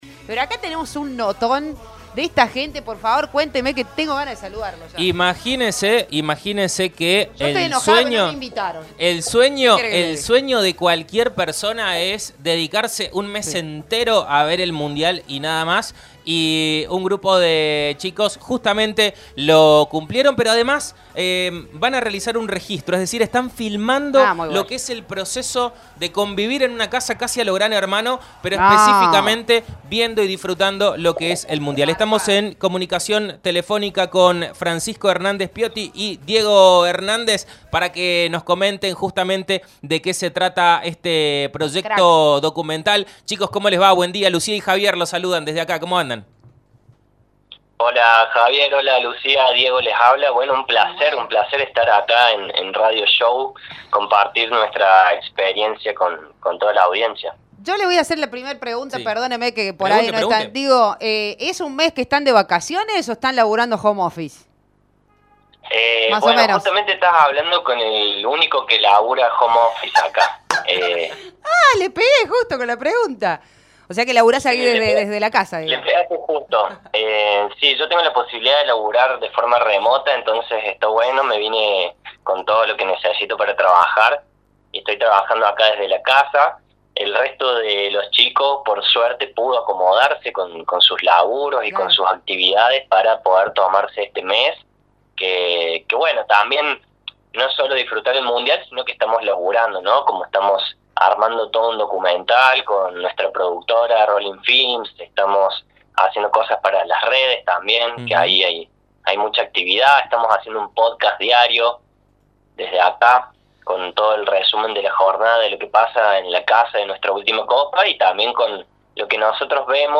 En la radio conversamos con